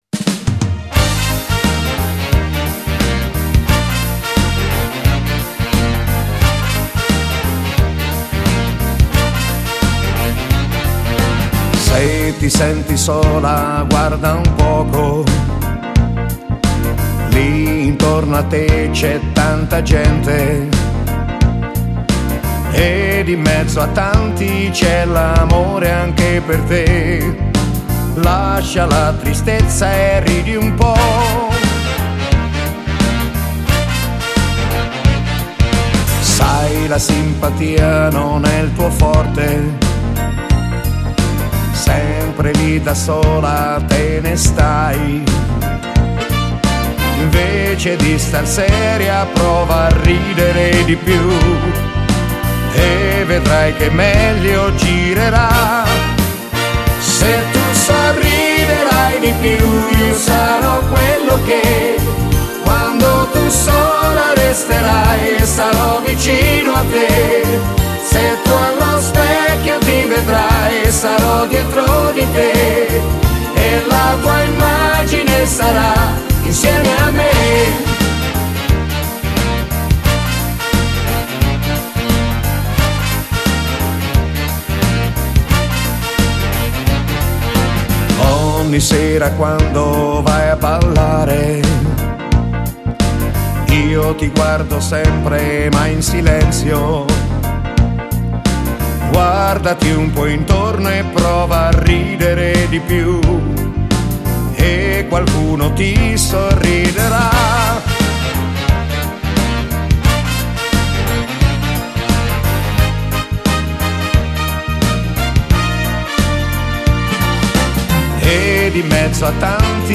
Genere: Fox trot